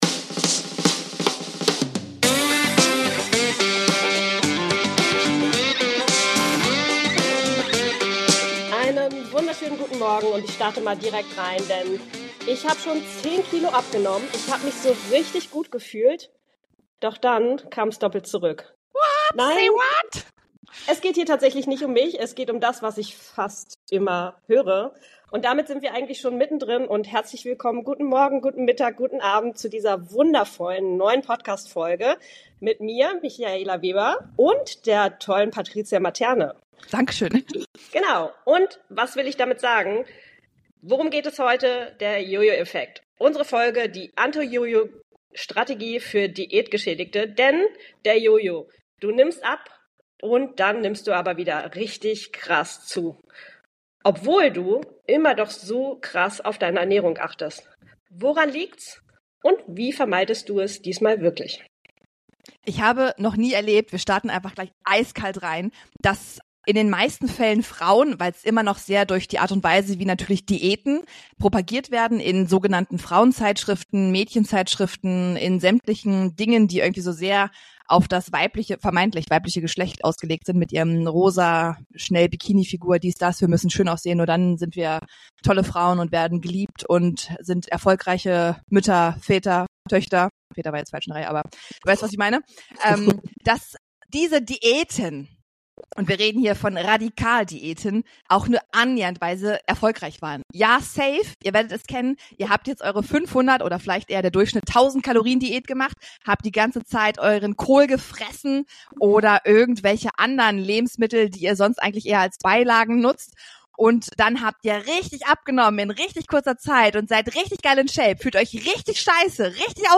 In dieser Episode wird der Jojo-Effekt und seine biologischen Grundlagen diskutiert. Die Hosts erläutern, wie Diäten oft zu metabolischen Anpassungen führen, die den Körper in einen Überlebensmodus versetzen.